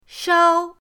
shao1.mp3